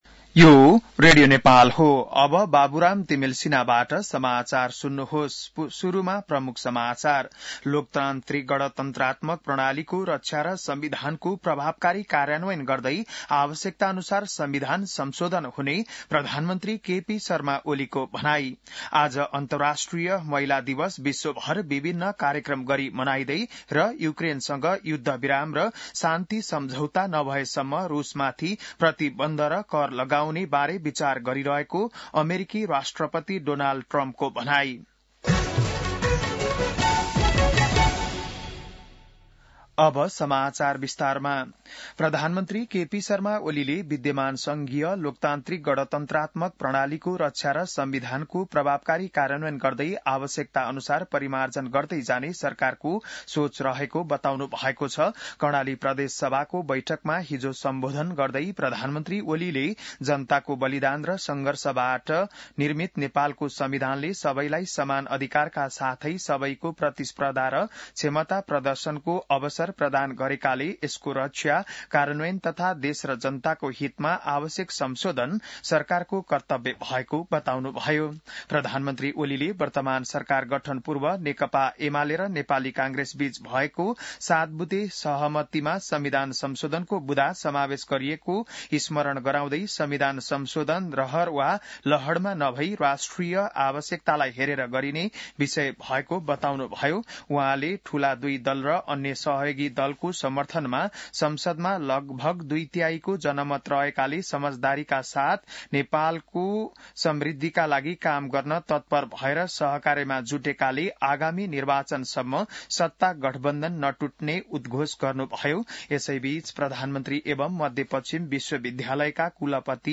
बिहान ९ बजेको नेपाली समाचार : २५ फागुन , २०८१